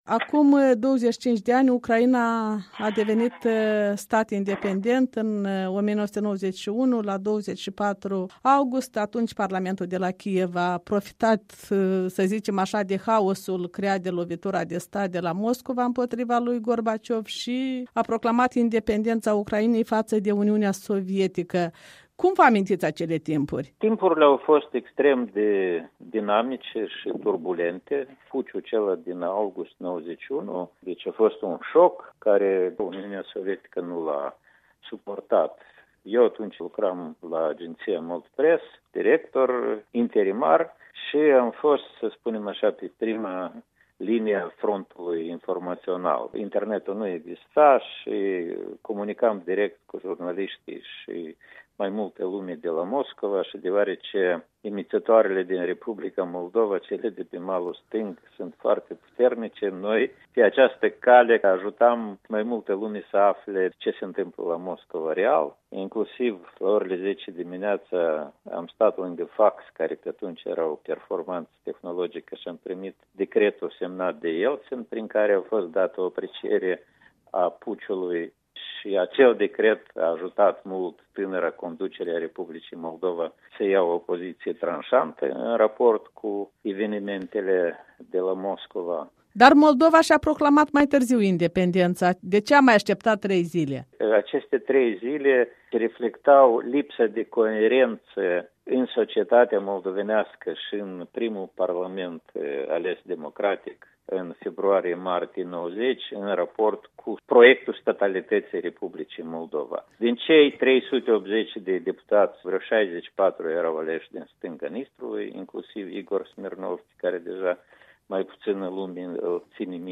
Interviu cu Oazu Nantoi